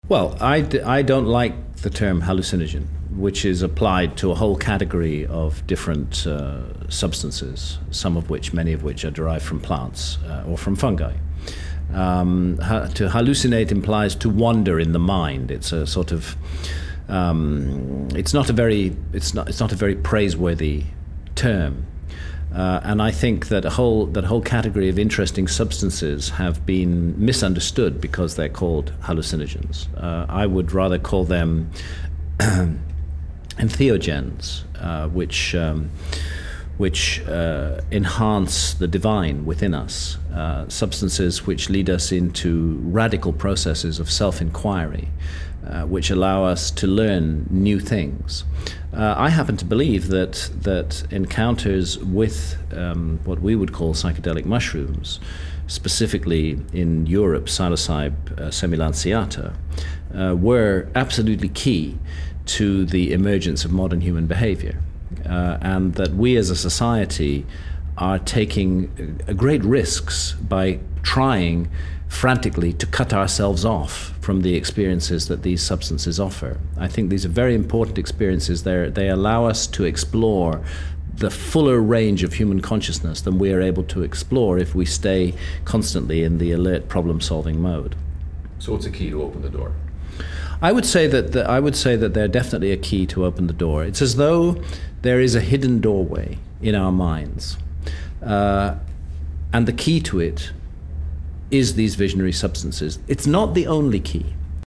INTERVIEW: Graham Hancock Speaks Out
Then as the chairs were being folded and with only minutes before leaving for the airport, he graciously agreed to sit down with me and field a few questions: